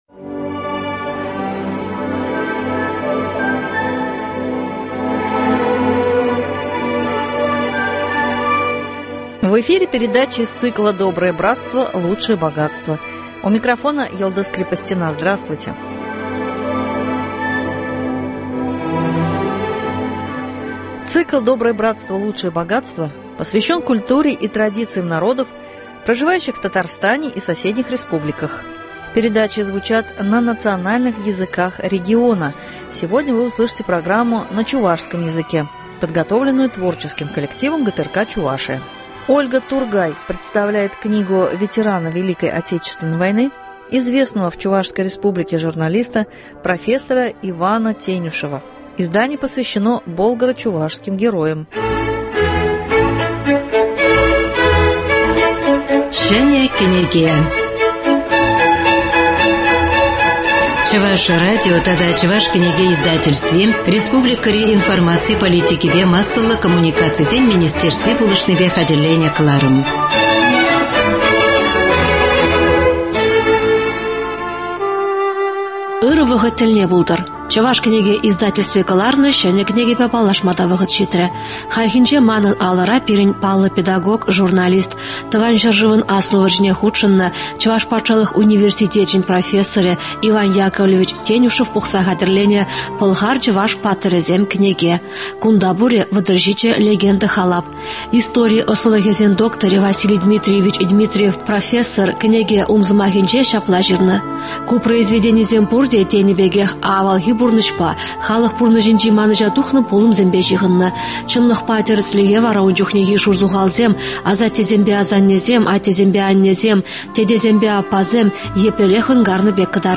чувашским героям ( из фондов радио).